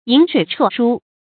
饮水啜菽 yǐn shuǐ chuò shū 成语解释 饿了吃豆羹，渴了喝清水。
成语繁体 飲水啜菽 成语简拼 yscs 成语注音 ㄧㄣˇ ㄕㄨㄟˇ ㄔㄨㄛˋ ㄕㄨ 常用程度 常用成语 感情色彩 中性成语 成语用法 联合式；作谓语、定语；含贬义 成语结构 联合式成语 产生年代 古代成语 近 义 词 饮水食菽 成语例子 宋·苏辙《答黄庭坚书》：“独颜氏子 饮水啜菽 ，居于陋巷，无假于外。”